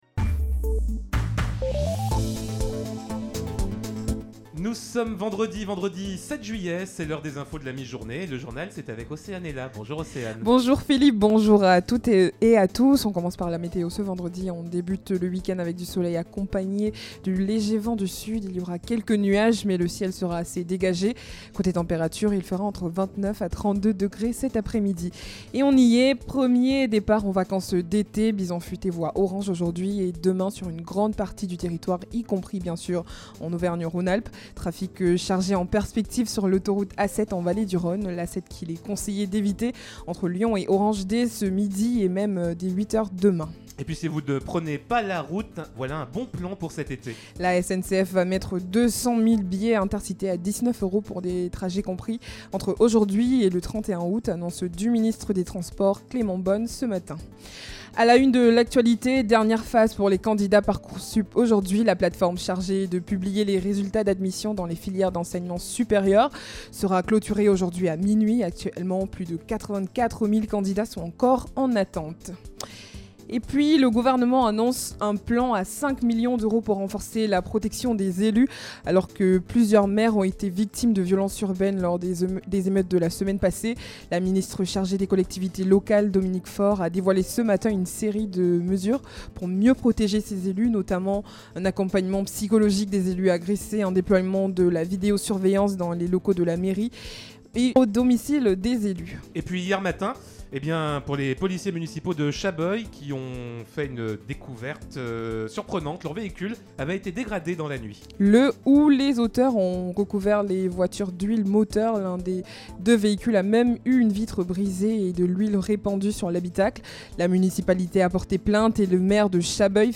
in Actualités, Journal du Jour - Flash
Vendredi 07 juillet: Le journal de 12h